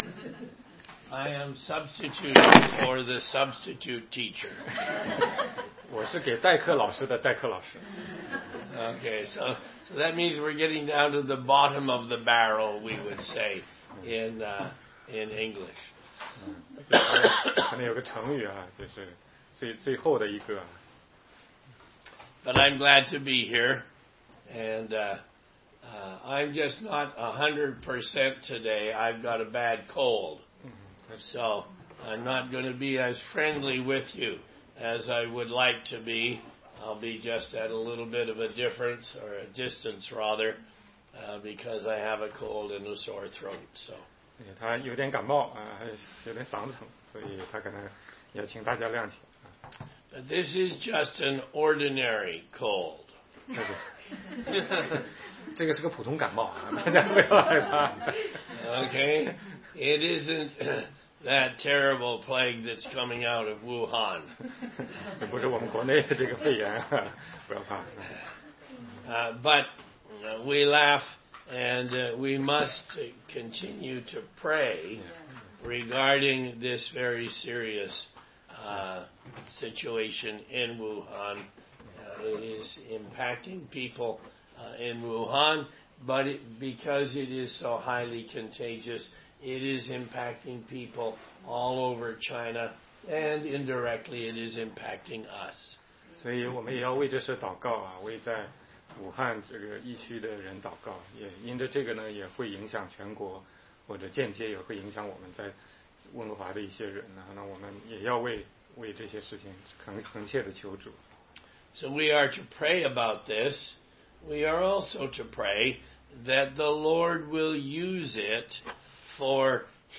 16街讲道录音 - 毕世大池子的瘫子